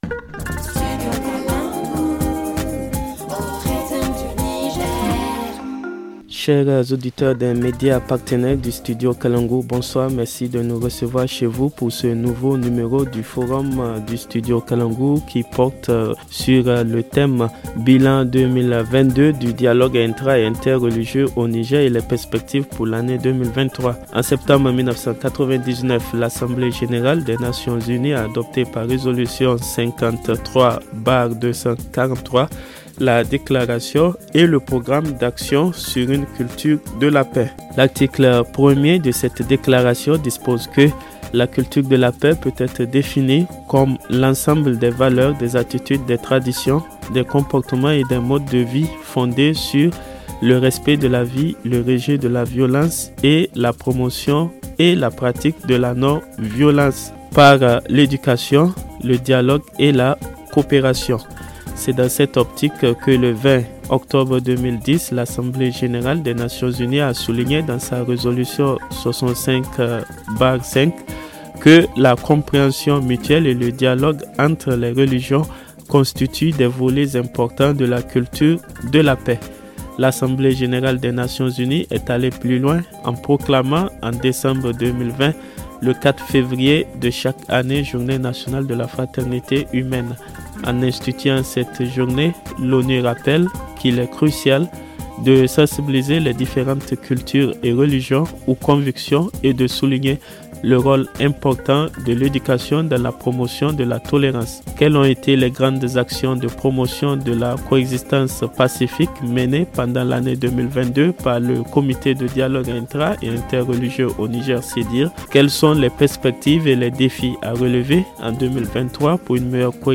ok-FORUM-BILAN-ET-PERSPECTIVES-DIALOGUE-INTRA-ET-INTERRELIGIEUX-AU-NIGER-0802.mp3